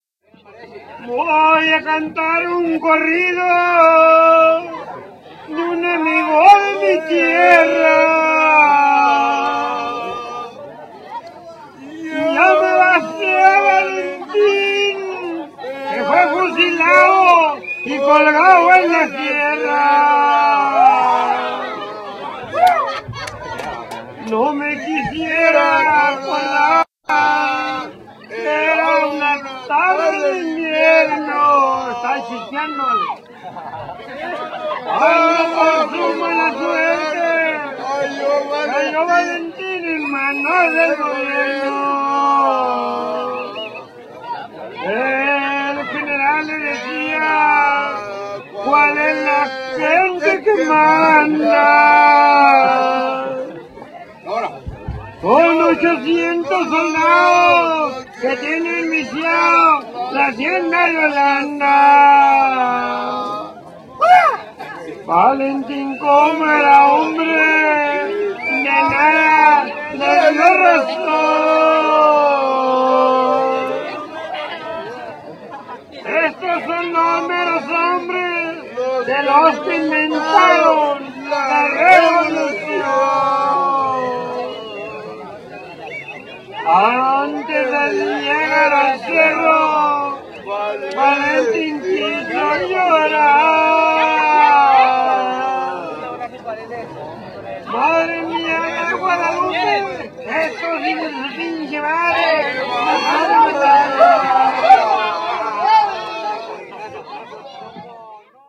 MEXICAN SINGER Cantante Picture and Audio Clip Recording During Fiesta in Indian Tribal Village Baja California Mexico
CANTANTE MEJEICANOR
A traditional Mexican singer — cantante mejicanor — in full Pancho Villa style costume, complete with large sombrero, boots, fancy pants, and a pancho, sang Mexican songs live into a microphone at the festival.
Cantante.m4a